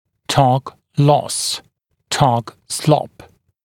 [tɔːk lɔs] [slɔp][то:к лос] [слоп]потеря (провал) торка (часто из-за ненадлежащего качества паза брекета, дуги, неправильного позиционирования и других факторов)